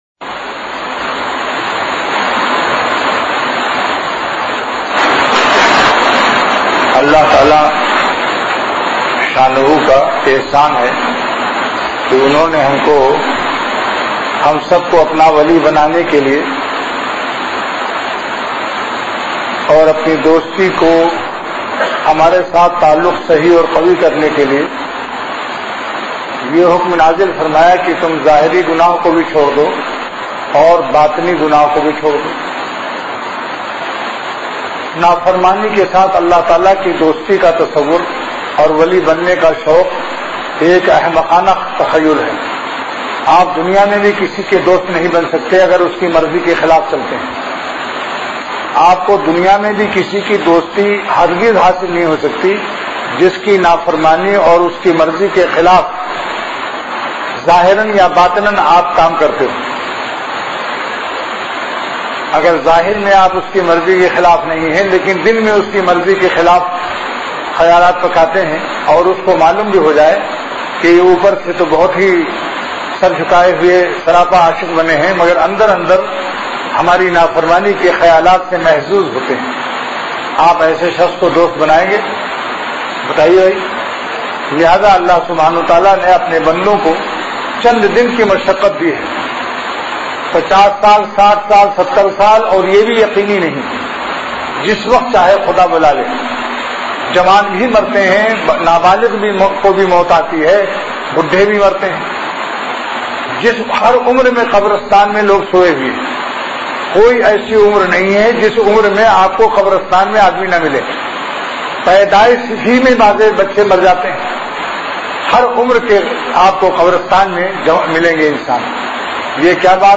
مجلس کے آغاز میں حضرت والا رحمۃ اللہ علیہ کا بہت درد بھرا بیان کیسٹ سے سنوایا گیا